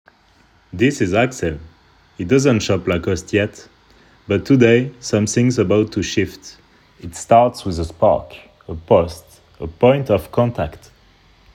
Bandes-son
15 - 50 ans - Baryton